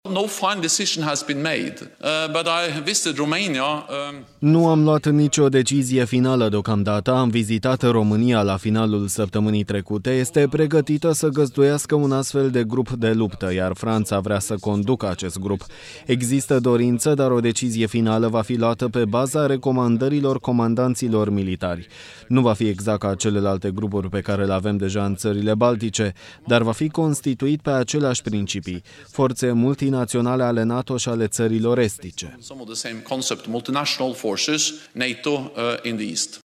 16feb-19-Jens-despre-grupul-de-lupta-din-romania-TRADUS.mp3